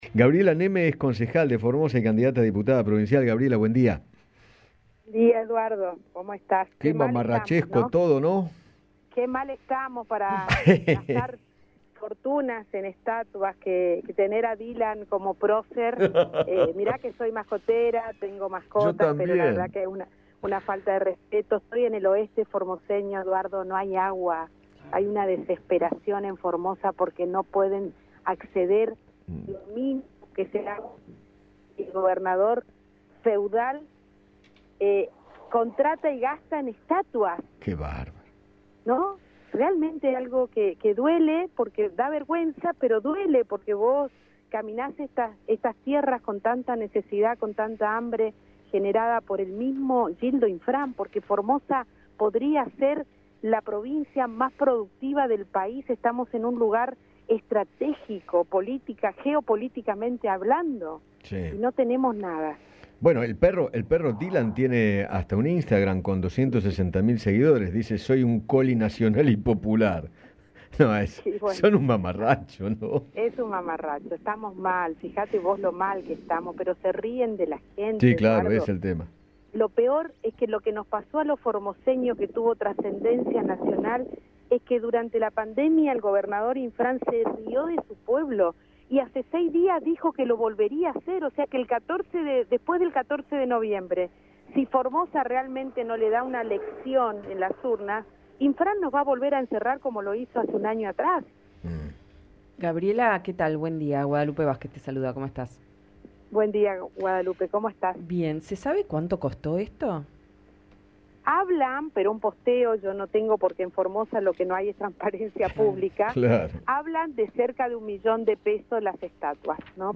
habló con Eduardo Feinmann sobre la polémica estatua que instalaron en aquella provincia, en la que están representados los dos perros del Presidente